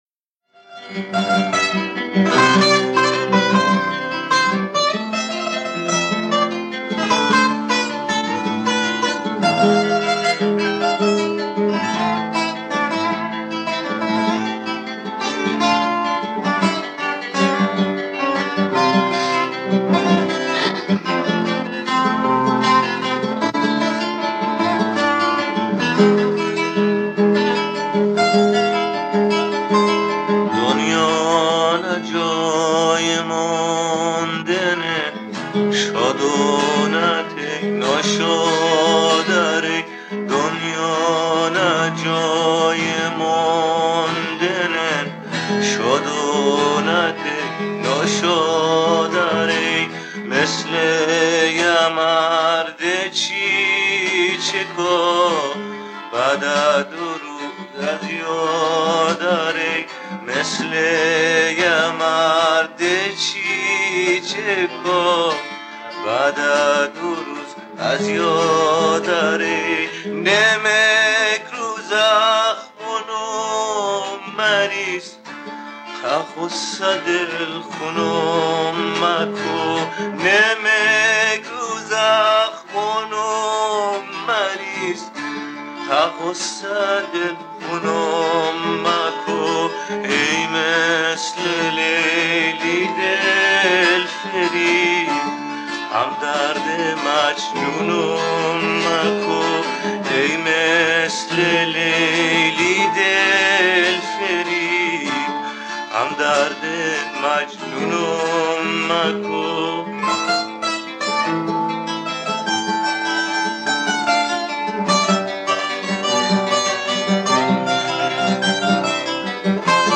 آهنگ جنوبی
(بندری)